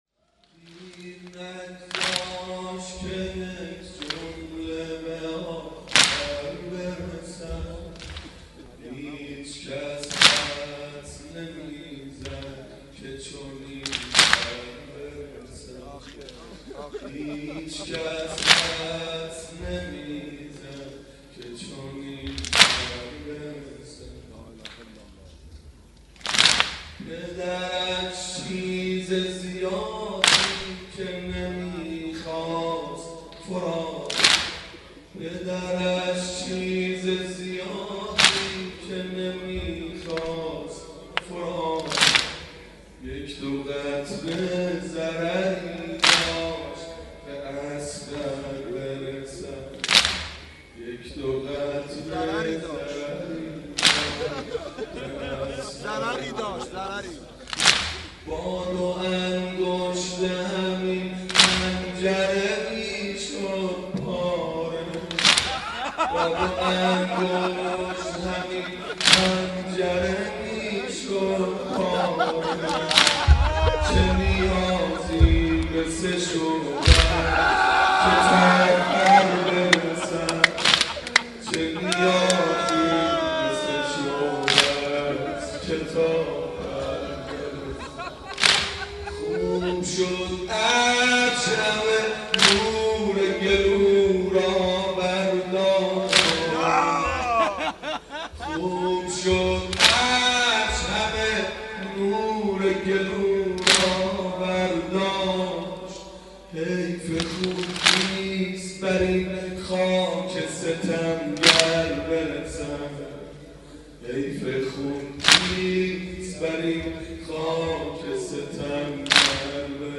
مناسبت : شب هفتم محرم
مداح : حاج منصور ارضی قالب : واحد